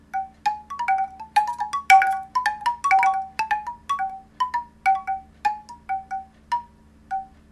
Bambusová zvonkohra – 40 cm
Bambusová zvonkohra na rozdiel od ostatných zvonkohier vydáva jemný, očarujúci a nevtieravý zvuk.
Táto zvonkohra je vyrobená z bambusu a kokosu. Ručné tónovanie a výber rôznych veľkostí rúrok, z ktorých každá vydáva iný zvuk, vytvárajú jedinečnú melódiu už pri najmenšom vánku.
Zvonkohra-40-cm.mp3